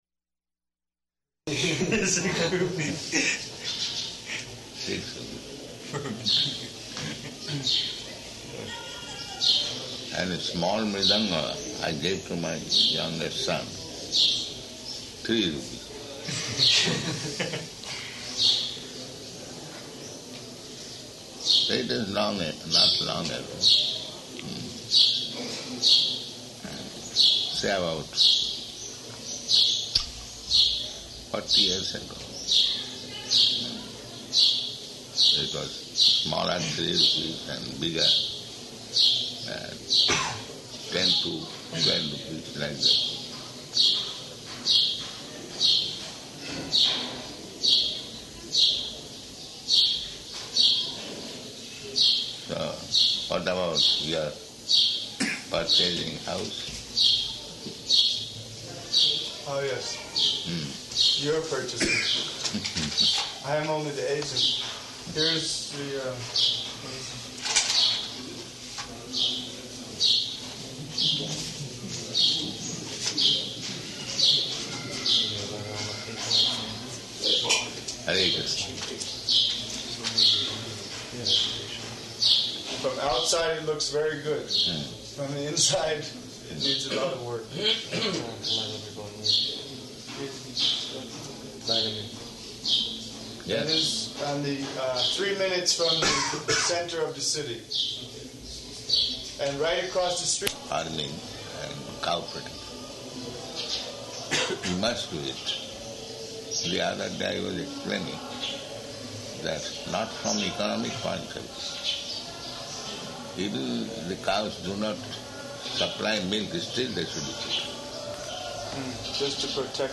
-- Type: Conversation Dated: February 25th 1977 Location: Māyāpur Audio file